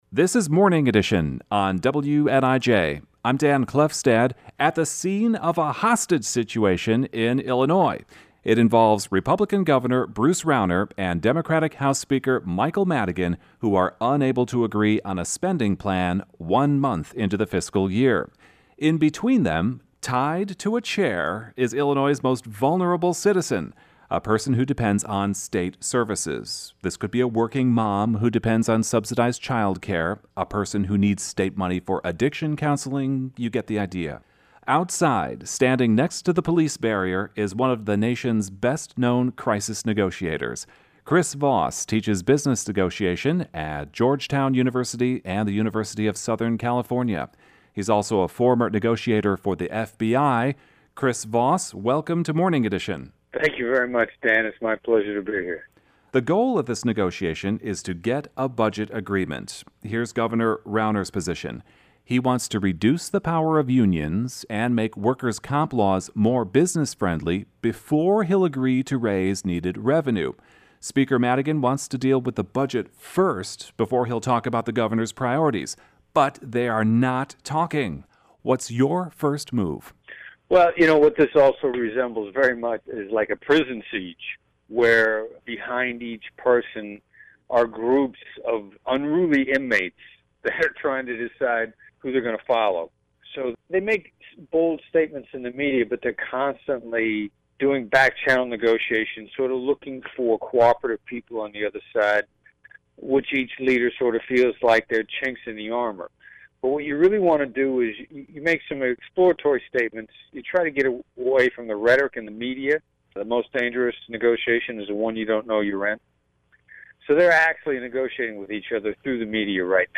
Morning Edition interview (Aug. 6, 2015).